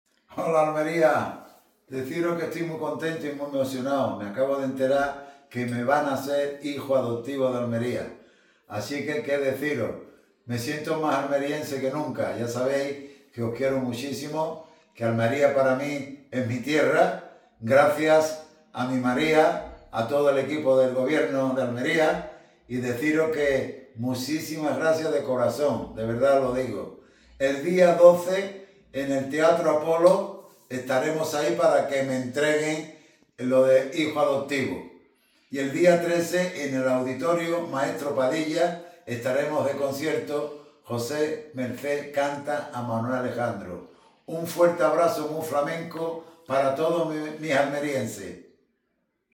Tras su intervención, se ha proyectado un saludo grabado por el cantaor con el que, con la simpatía y naturalidad que le caracteriza, ha mandado “un fuerte abrazo muy flamenco para todos los almerienses”, después de agradecer a la alcaldesa y al Equipo de Gobierno el nombramiento como hijo adoptivo, que le hará sentirse “más almeriense que nunca porque Almería es también mi tierra”.